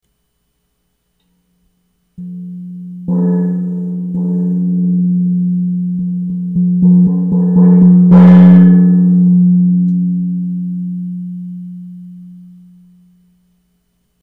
缶どら　＜2005年＞
バチはファックス用紙の芯に起毛の古トレーナーの布を巻いて輪ゴムでとめました。
作り方　写真のものは箱に固定してありますが、いい音のする缶を輪ゴムでつるしてバチで叩くだけでＯＫです。